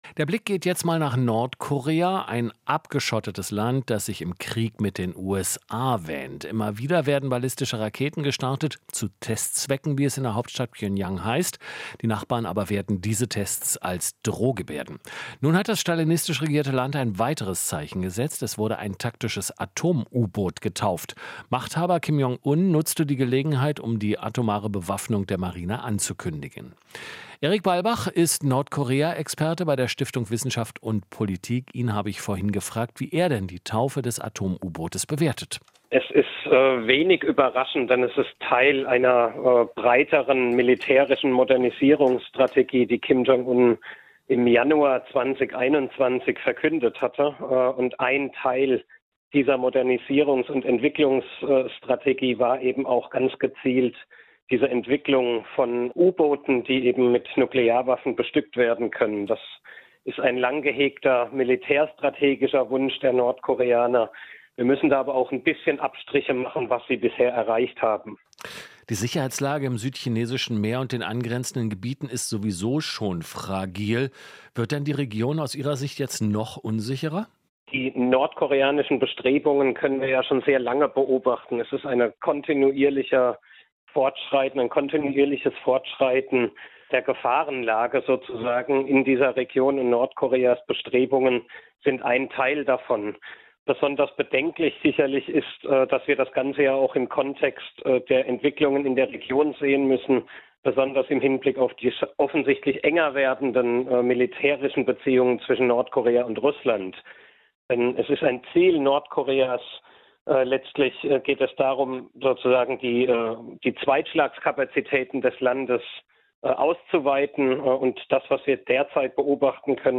Interview - Nordkorea-Experte: Atom-U-Boot ist Teil einer militärischen Strategie